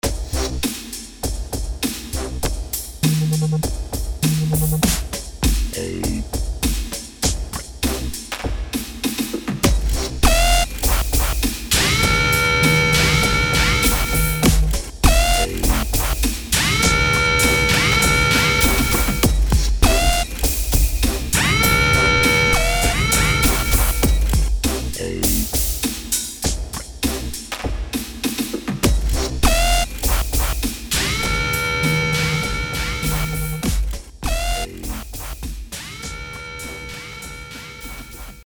• Качество: 320, Stereo
громкие
dance
Electronic
без слов
инструментальные
techno
Ритмичный рингтон для звонка